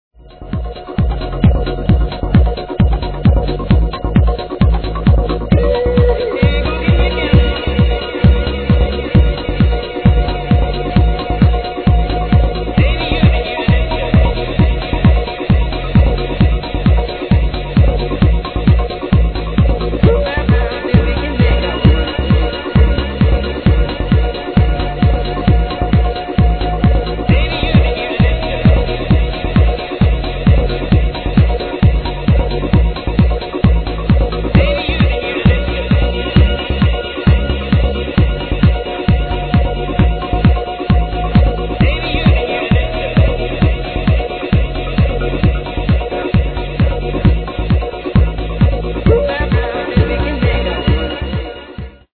amazing deep progressive track